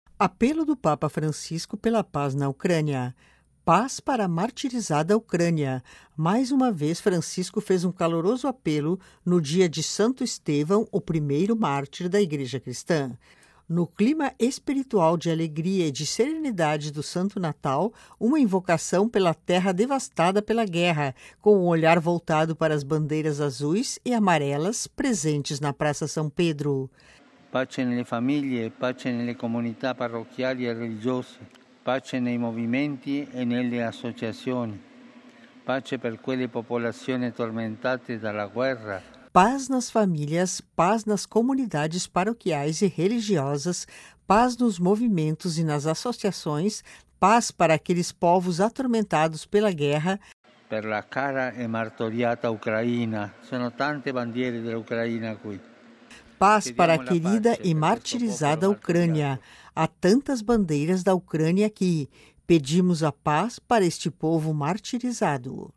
Angelus de 26 de dezembro na Praça São Pedro  (Vatican Media)